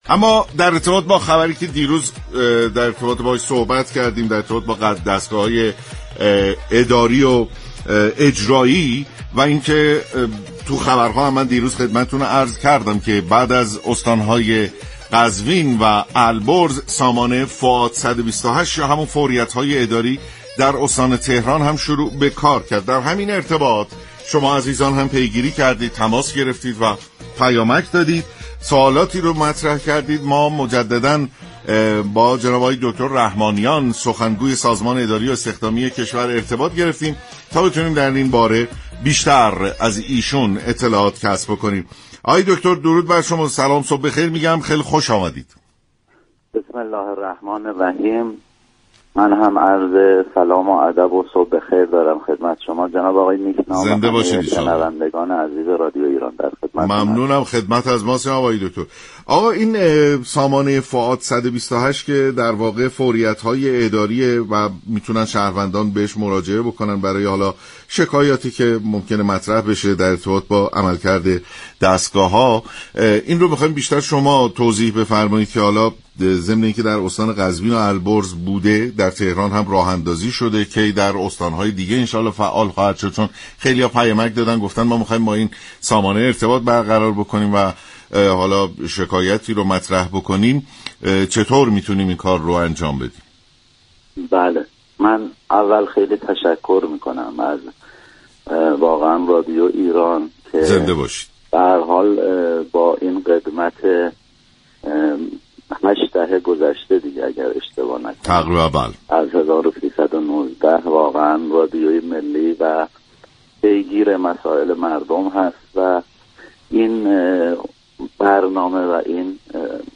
برنامه سلام‌صبح‌بخیر شنبه تا پنج‌شنبه هر هفته ساعت 6:15 از رادیو ایران پخش می‌شود.